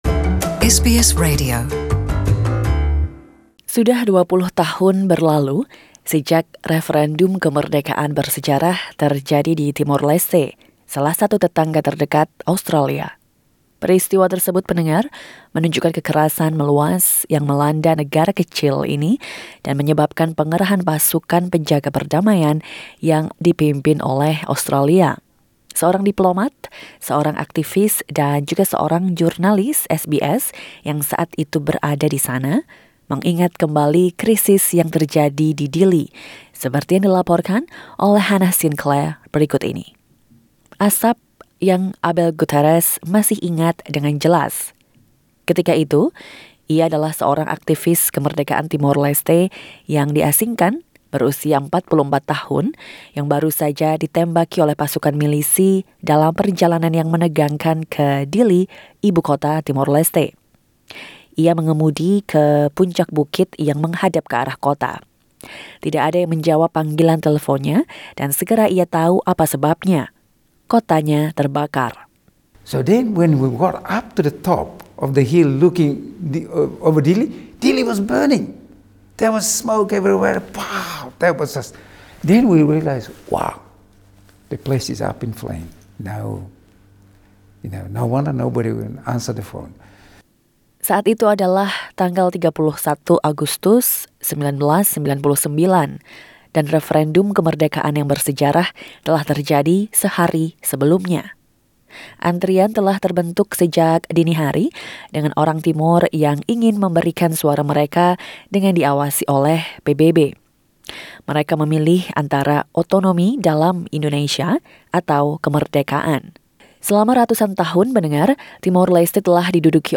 A diplomat, an activist and an SBS journalist who were there, recall the crisis unfolding in Dili.